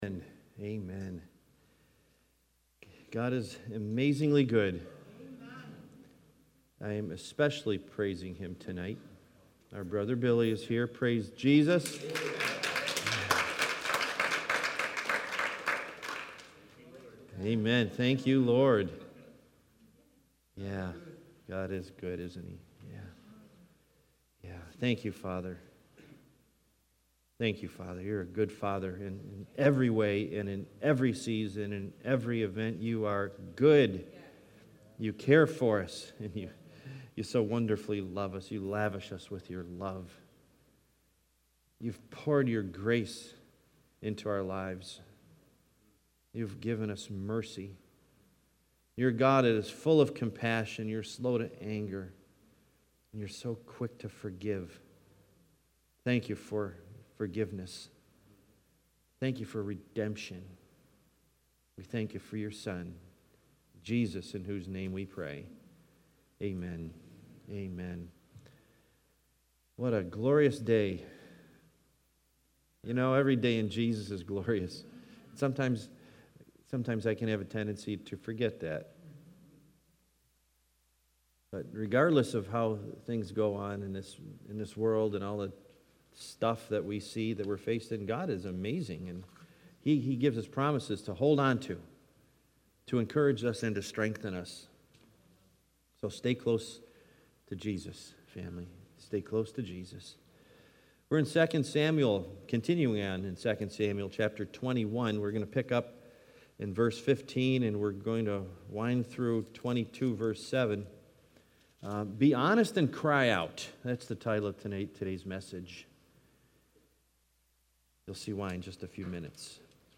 Series: Wednesday Bible Study